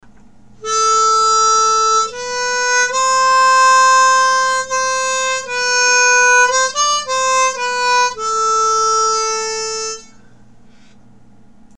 We’re using a G major diatonic and playing in 1st position.